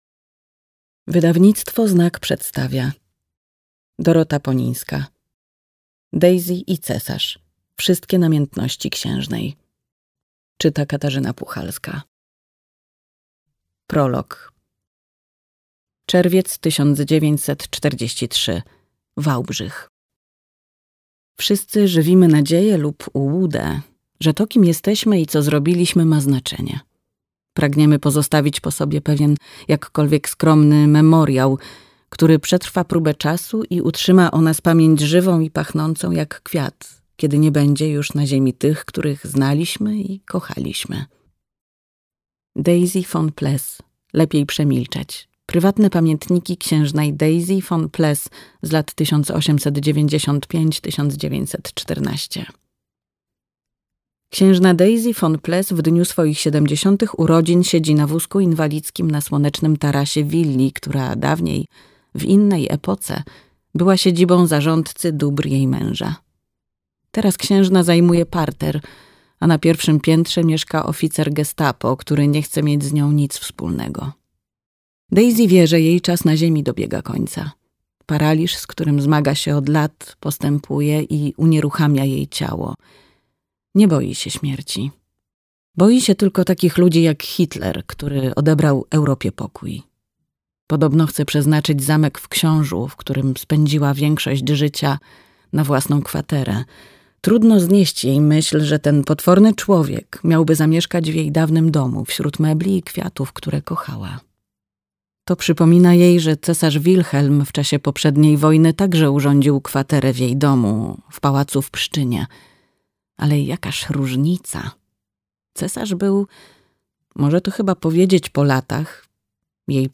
Daisy i cesarz. Wszystkie namiętności księżnej - Dorota Ponińska - audiobook